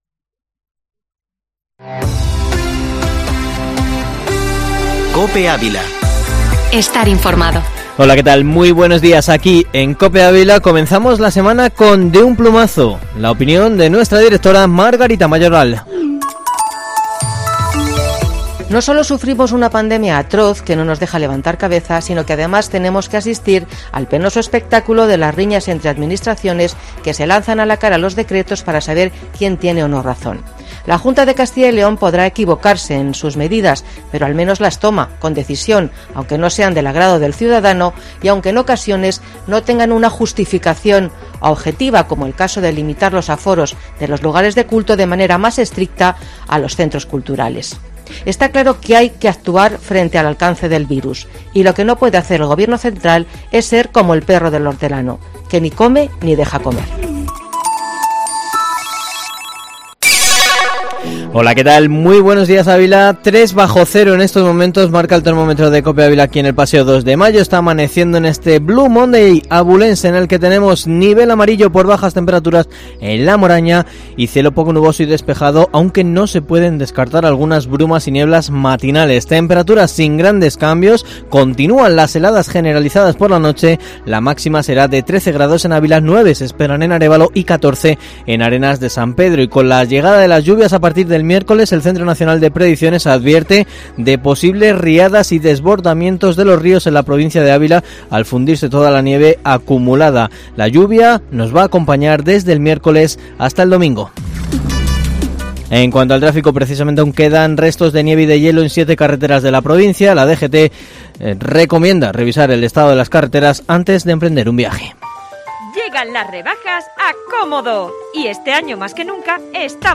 Informativo matinal Herrera en COPE Ávila 18/01/2021